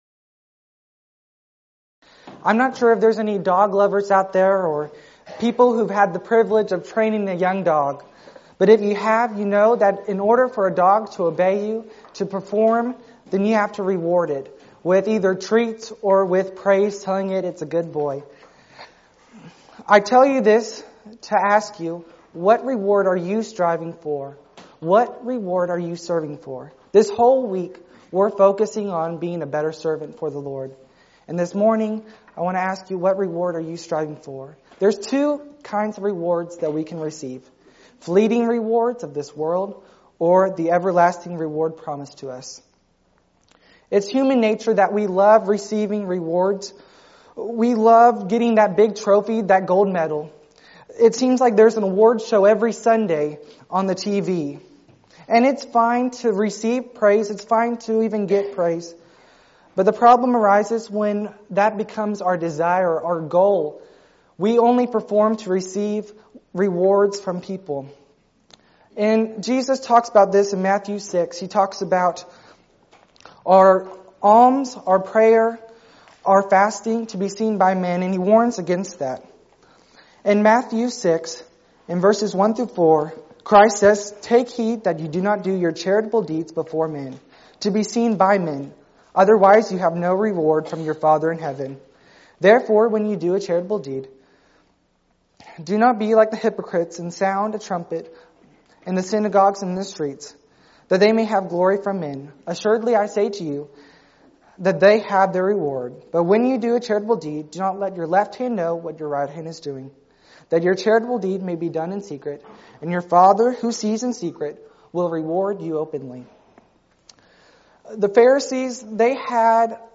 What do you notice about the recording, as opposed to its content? Event: 1st Annual Arise Workshop